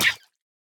Minecraft Version Minecraft Version snapshot Latest Release | Latest Snapshot snapshot / assets / minecraft / sounds / mob / axolotl / hurt1.ogg Compare With Compare With Latest Release | Latest Snapshot
hurt1.ogg